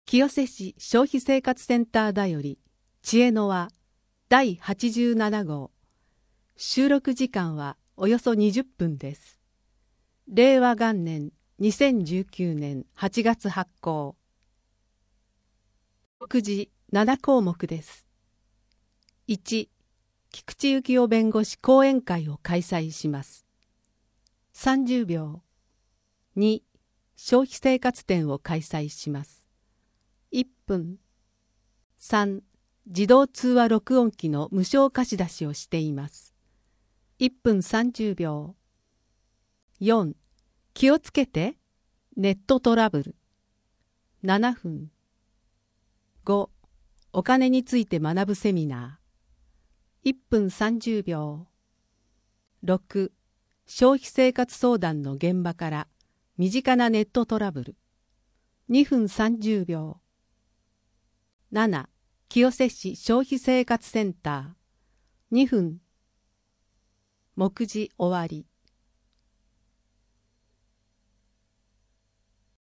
4面 消費生活相談の現場から 身近なネットトラブル 声の広報 声の広報は清瀬市公共刊行物音訳機関が制作しています。